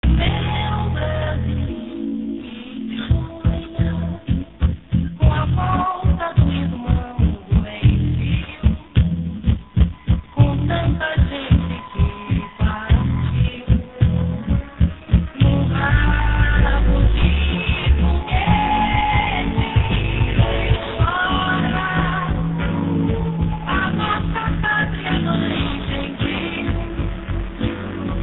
Stretch of music